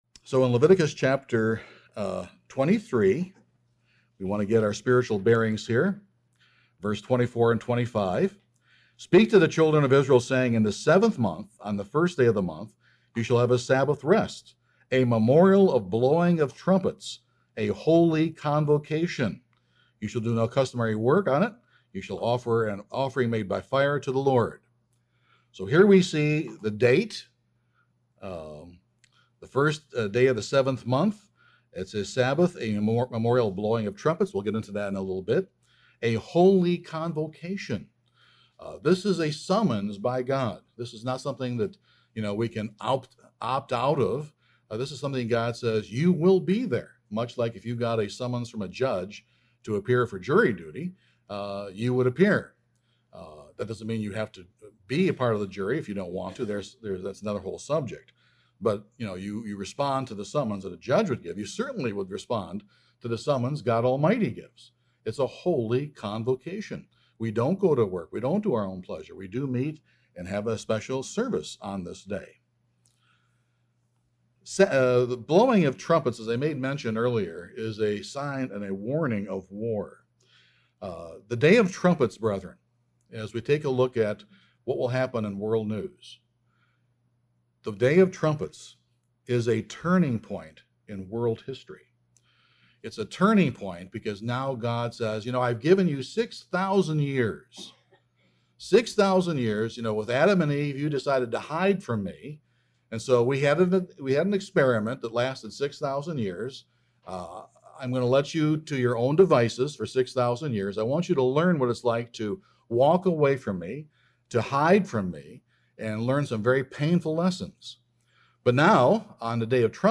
This sermon will outline the 7 Seals, 7 Trumpets, and 7 Plagues of the Book of Revelation.